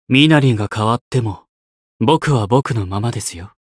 觉醒语音 身なりが変わっても、僕は僕のままですよ 媒体文件:missionchara_voice_167.mp3